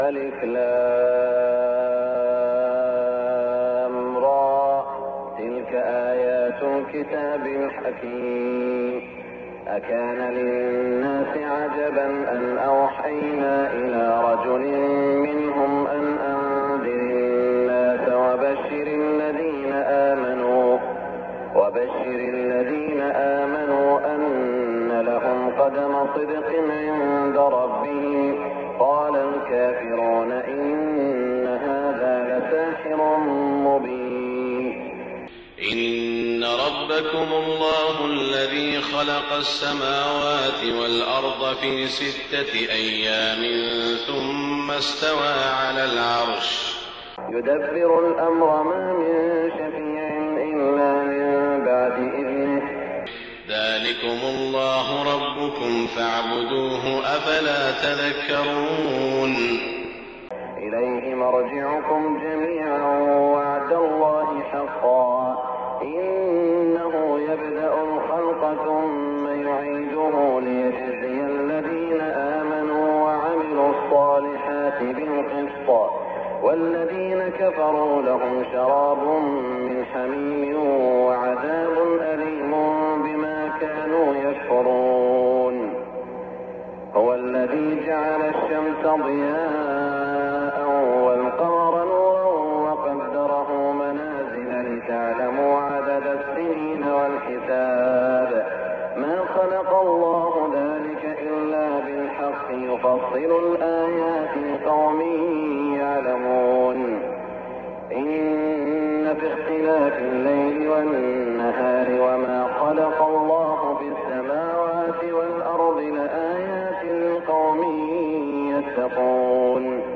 صلاة الفجر 1419هـ من سورة يونس > 1419 🕋 > الفروض - تلاوات الحرمين